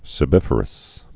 (sĭ-bĭfər-əs) also se·bip·a·rous (-bĭp-)